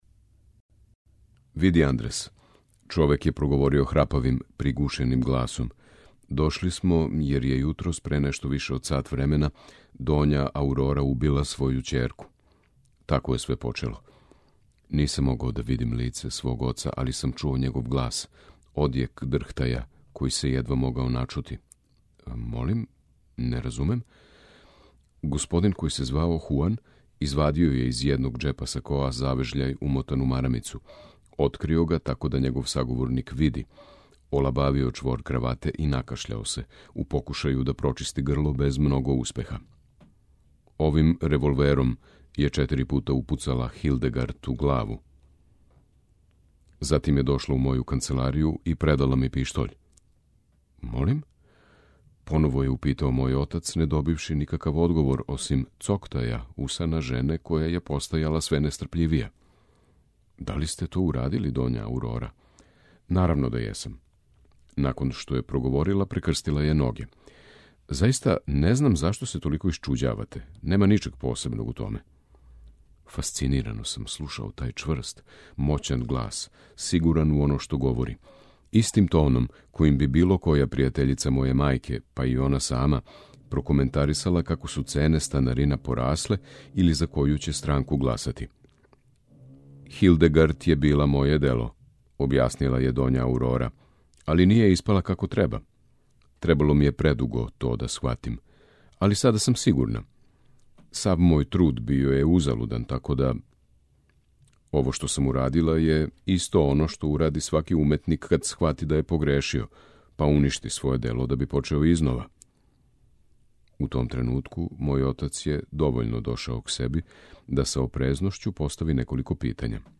У емисији Путеви прозе, можете слушати делове романа савремене шпанске списатељице Алмудене Грандес „Франкенштајнова мајка”.